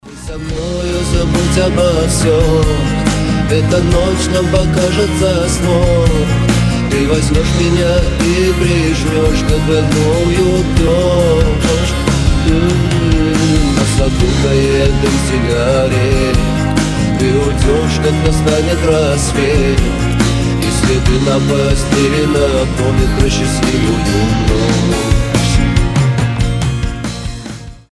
Рок рингтоны
Mashup